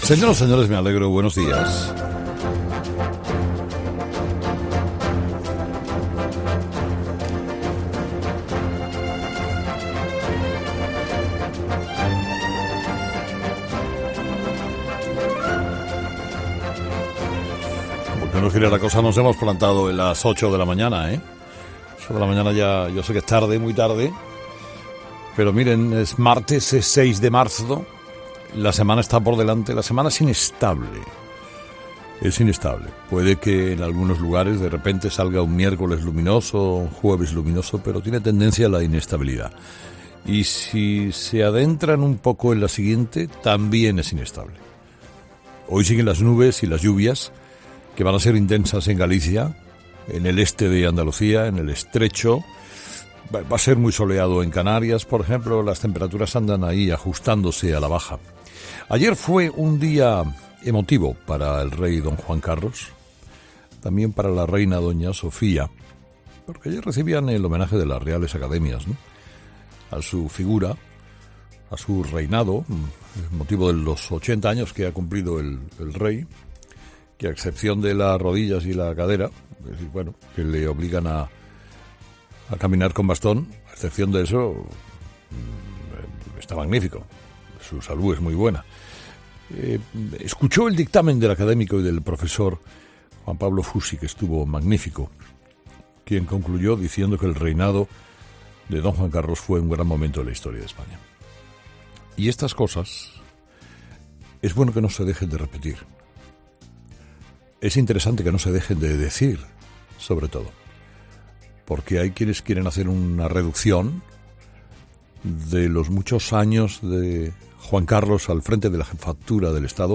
Monólogo de las 8 de Herrera 'Herrera en COPE'